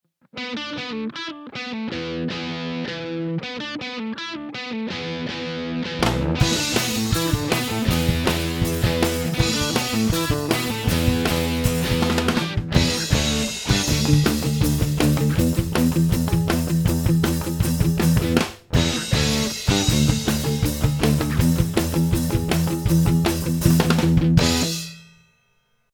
The introduction and the first verse (without the words)